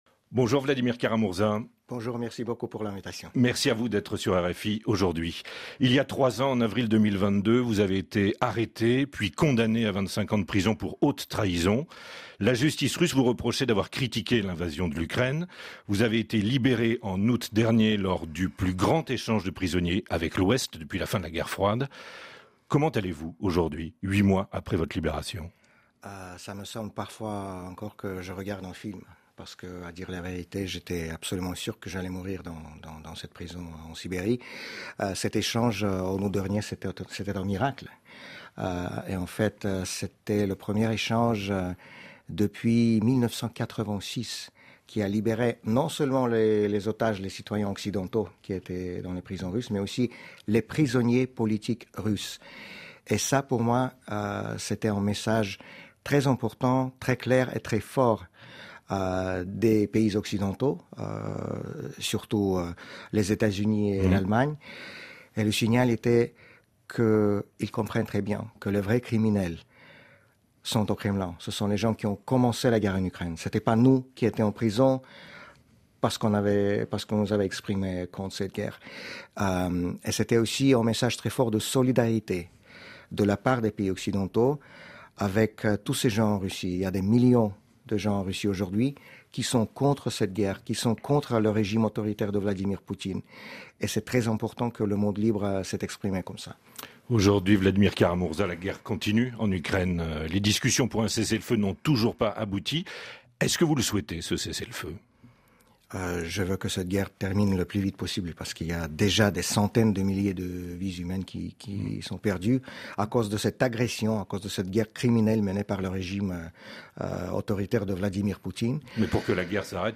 François Wu, vice-ministre taïwanais des Affaires étrangères, est l'invité de RFI ce 20 mars.
Il détaille la stratégie taïwanaise pour dissuader la Chine et ses attentes vis-à-vis des États-Unis de Donald Trump. Entretien.